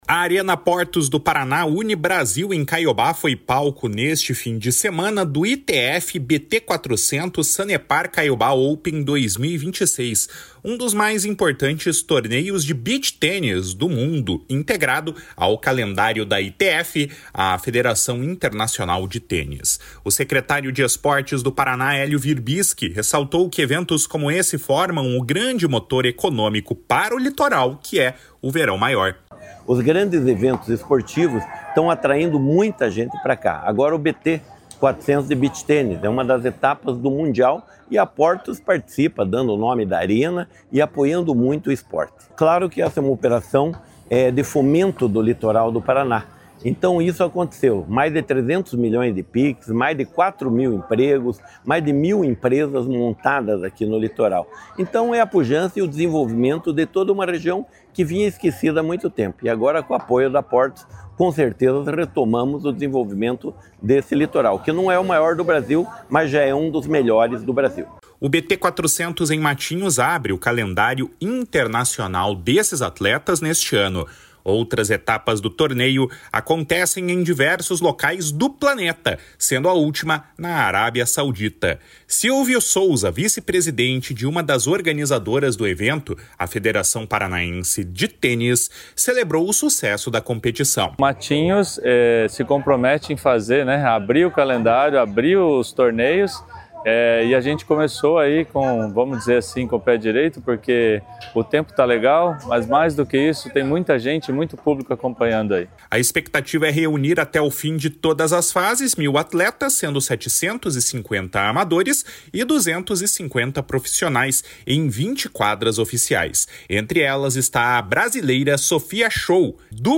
A Arena Portos do Paraná/UniBrasil, em Caiobá, foi palco, neste fim de semana, do ITF BT400 Sanepar Caiobá Open 2026, um dos mais importantes torneios de beach tennis do mundo, integrado ao calendário da ITF, a Federação Internacional de Tênis. O secretário de Esportes do Paraná, Hélio Wirbiski, ressaltou que eventos como esse formam o grande motor econômico que é o Verão Maior para o Litoral. // SONORA HÉLIO WIRBISKI //